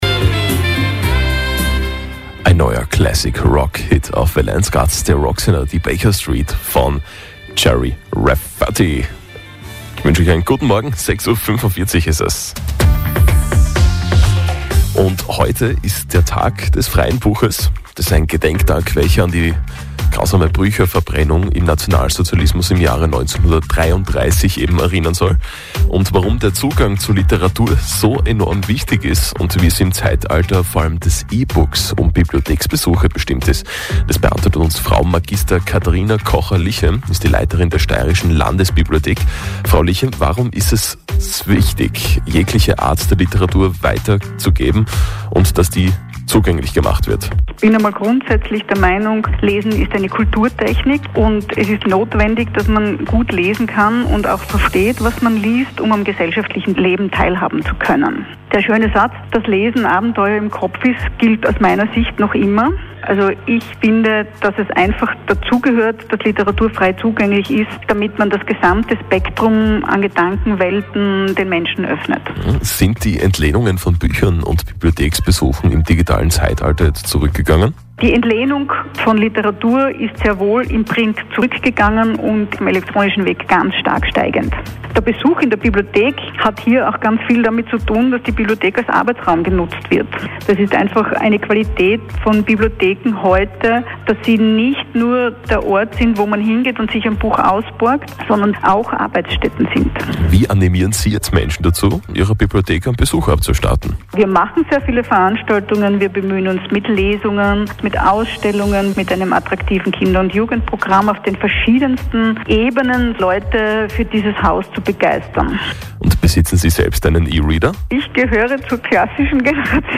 Mitschnitt Interview.mp3